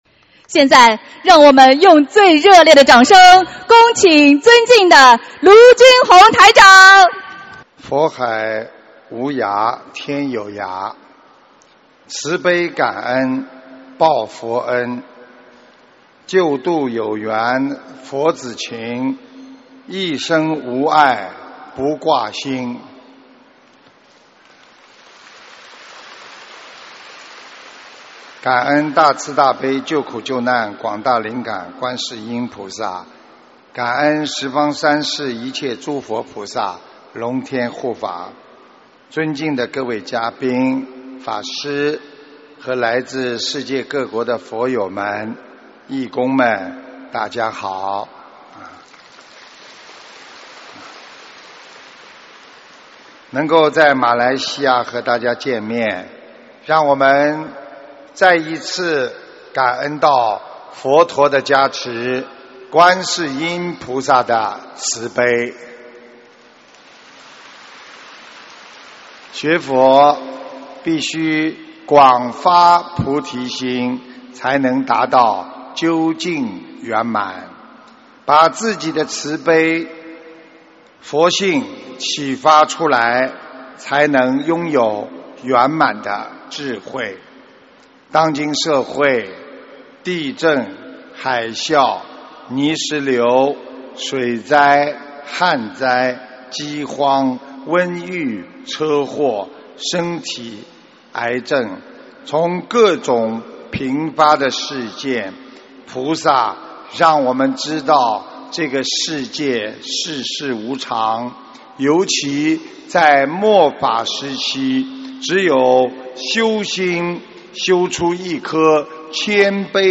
【2017马来西亚·吉隆坡】8月27日 大法会 文字+音频 - 2017法会合集 (全) 慈悲妙音